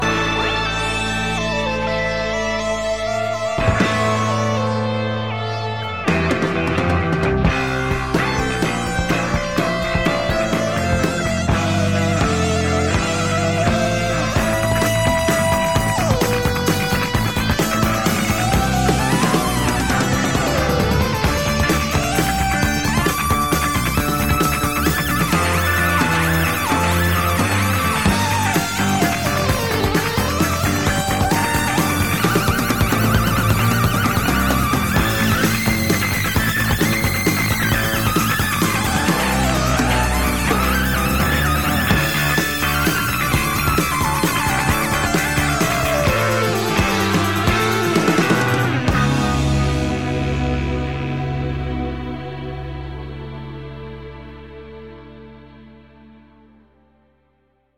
Specifically, the Mini solo here: